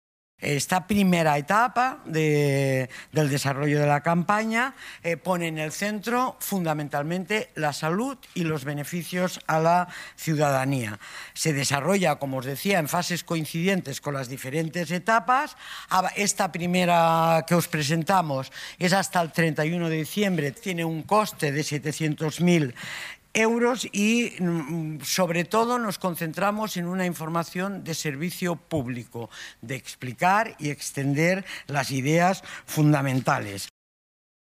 Inés Sabanés, delegada Medio Ambiente y Movilidad